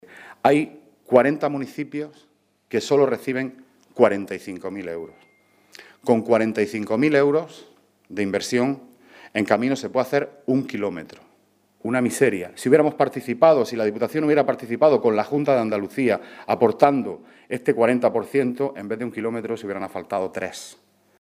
Rueda de prensa que ha ofrecido el portavoz del PSOE en la Diputación de Almería, Juan Antonio Lorenzo, acompañado por diputados provinciales